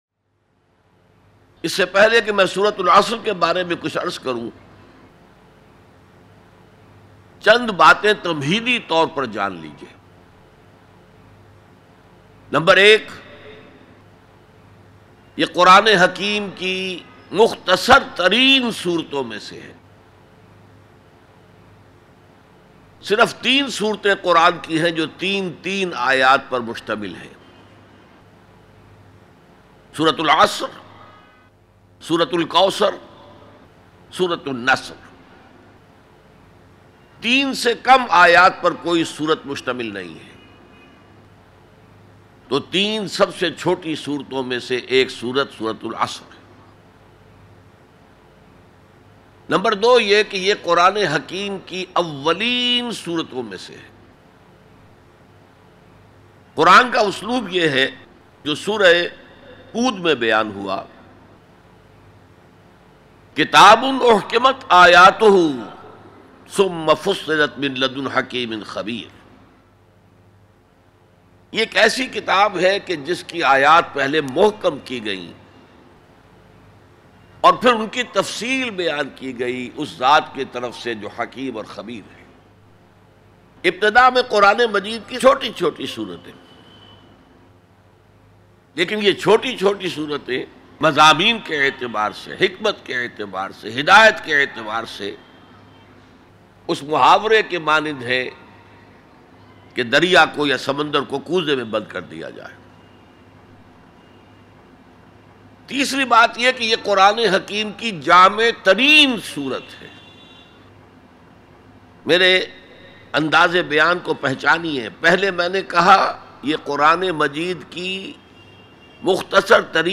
ALLAH Ki Insan Ko 4 Nasihaten Bayan MP3 Download By Dr Israr Ahmad